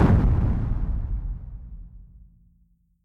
sounds_explosion_distant_03.ogg